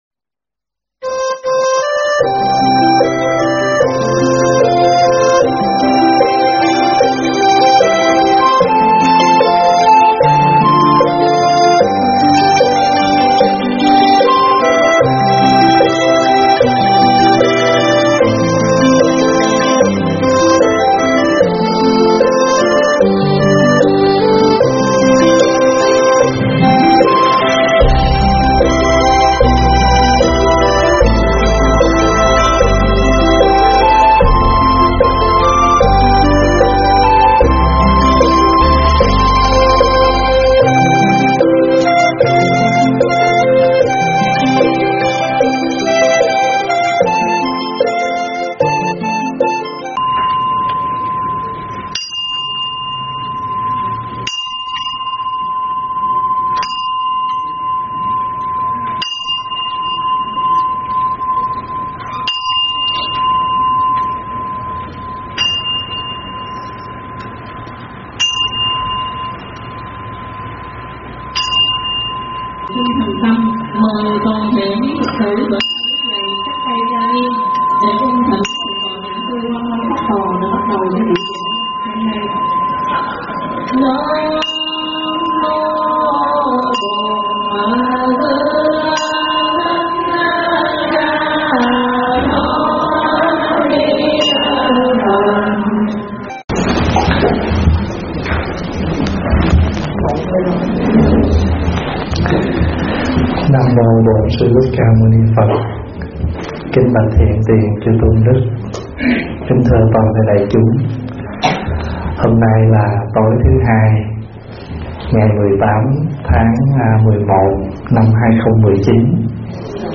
Thuyết pháp Ba Điều Hoan Hỷ